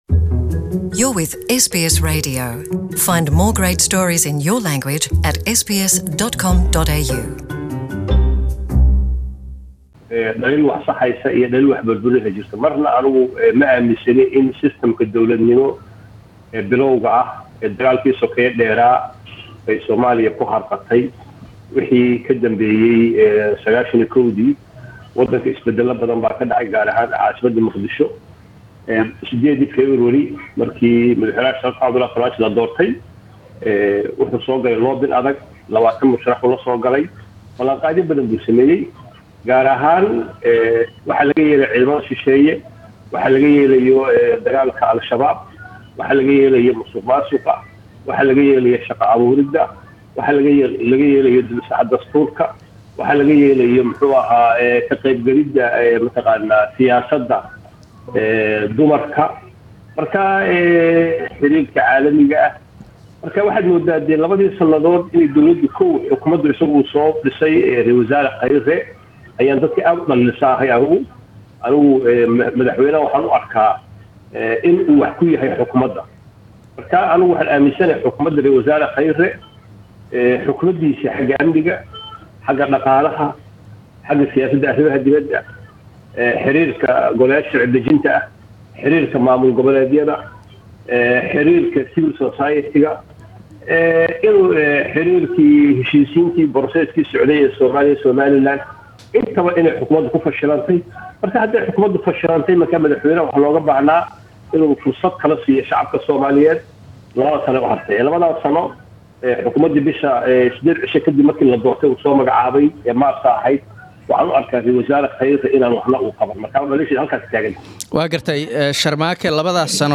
Debate: Second anniversary of president Farmajo's election